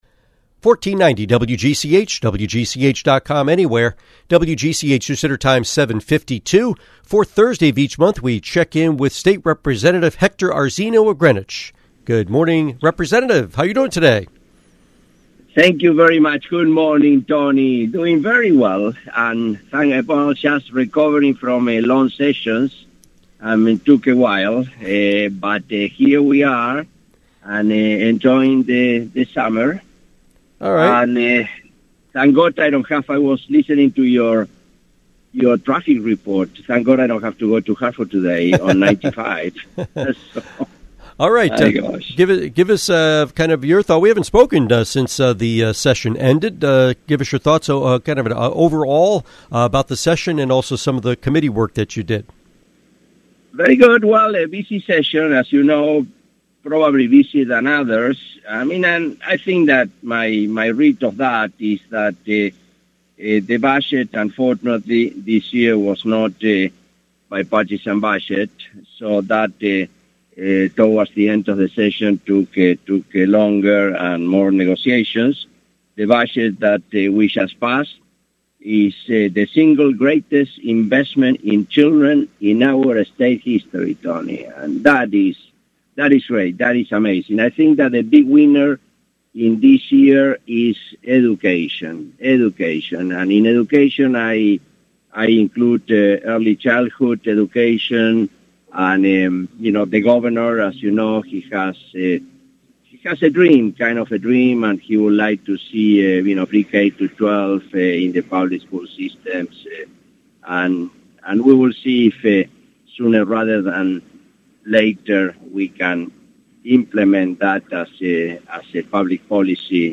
Interview with State Representative Hector Arzeno